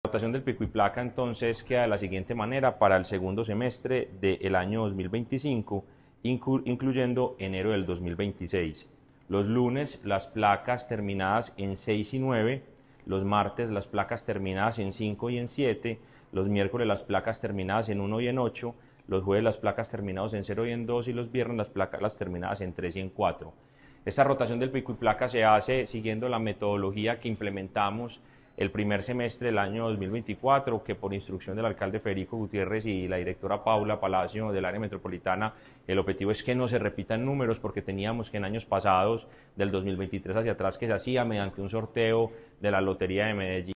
Declaraciones-del-secretario-de-Movilidad-Mateo-Gonzalez-Benitez-Pico-Y-Placa-segundo-semestre-2025.mp3